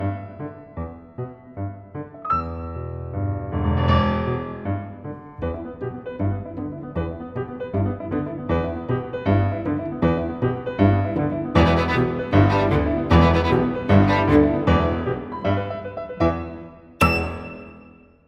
mehanizmy-konecz.mp3